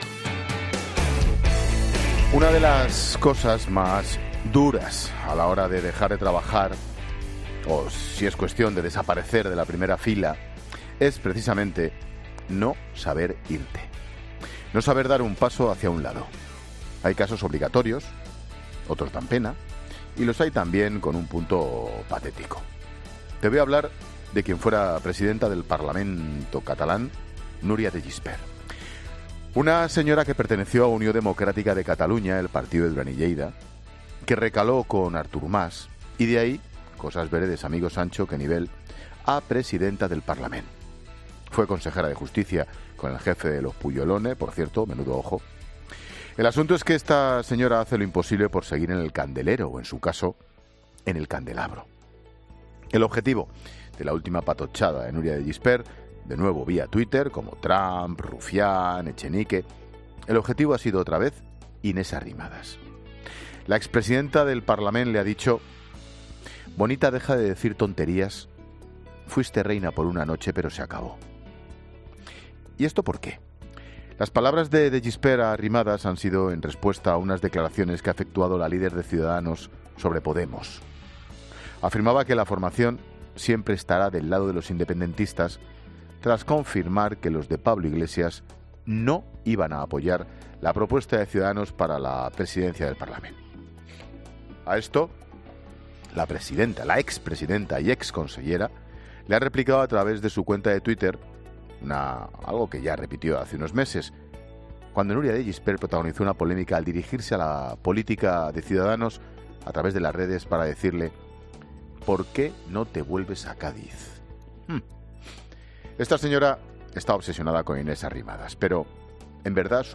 AUDIO: El comentario de Ángel Expósito sobre Nuria de Gispert.
Monólogo de Expósito